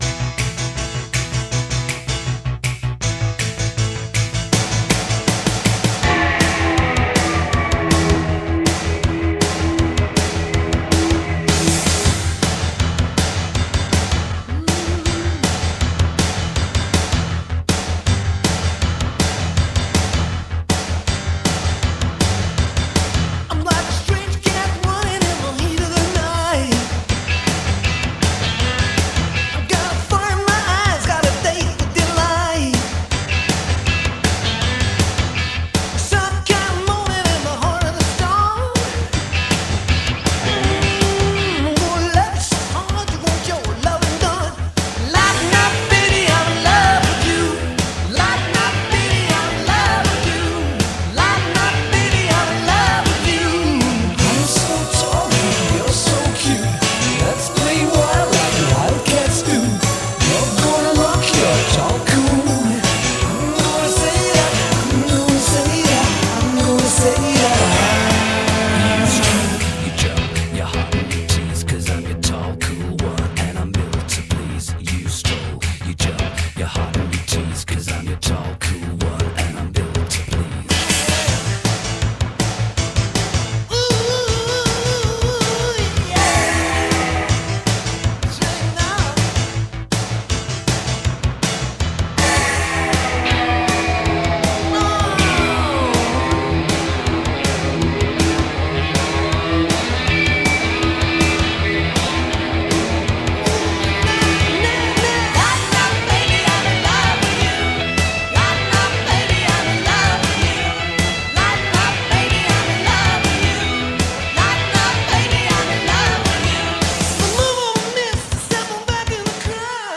BPM160
Audio QualityMusic Cut
But much to my surprise, it's a constant 159.5 BPM!